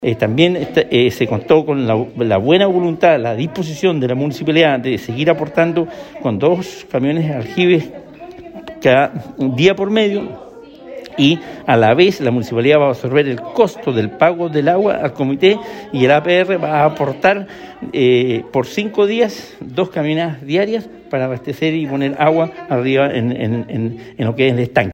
En lo relativo a soluciones de corto plazo, el gobernador informó que desde el municipio de Castro se comprometieron con la entrega de agua con la colaboración del comité de APR.